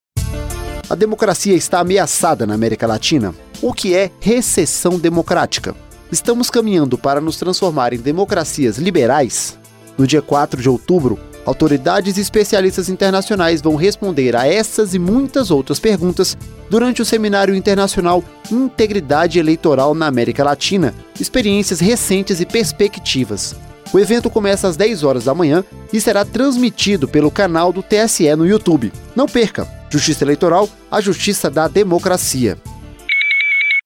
Spot: Participe do Seminário Internacional Integridade Eleitoral na América Latina – Experiências Recentes e Perspectivas